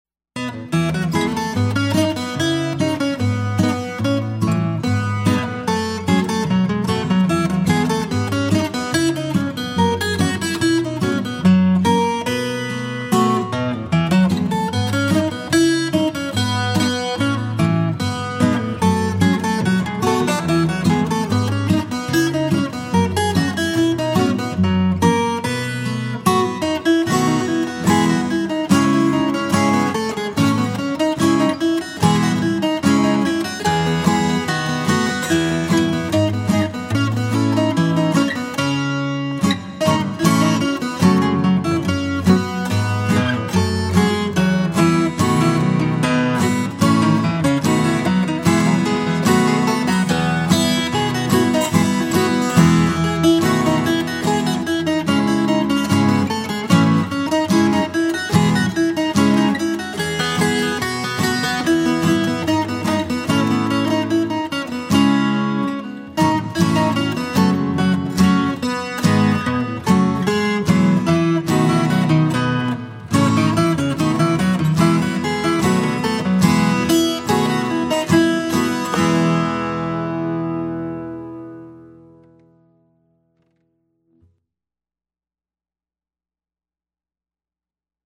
DIGITAL SHEET MUSIC - FLATPICK/PLECTRUM GUITAR SOLO